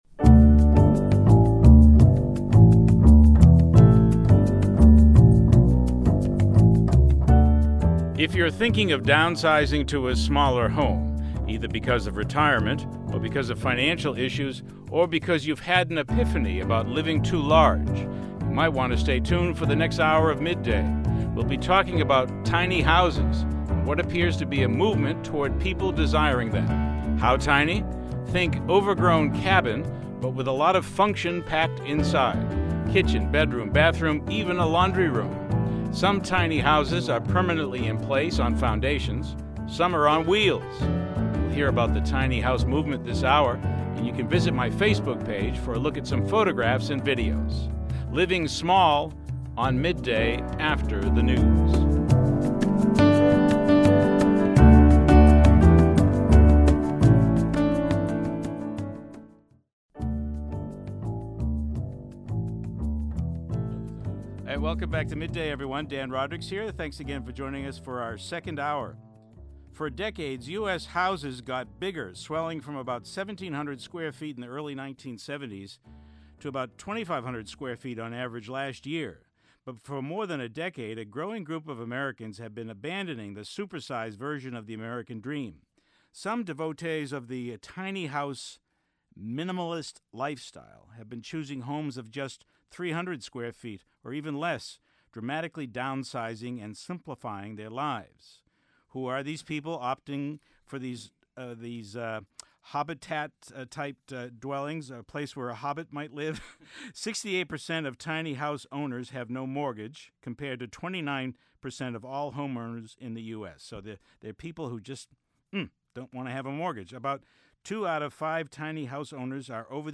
Click the play button below for the audio of the radio show.